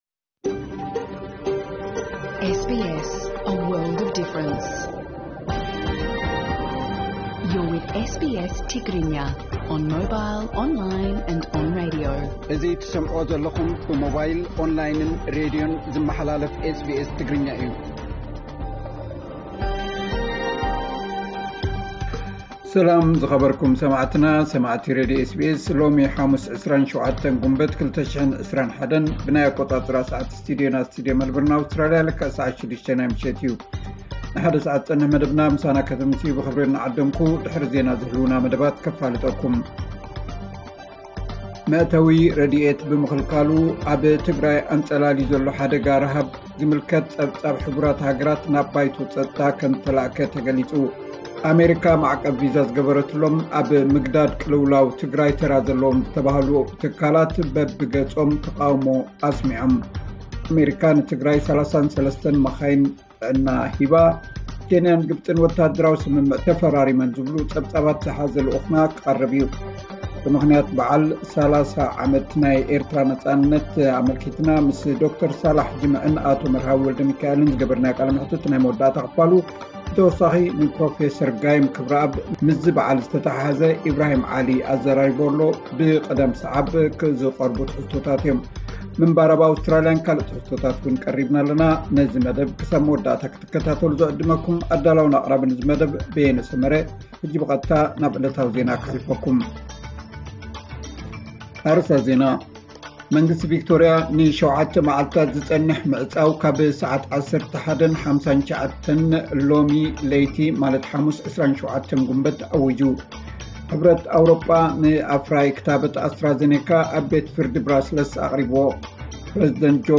ዕለታዊ ዜና 27 ግንቦት 2021 SBS ትግርኛ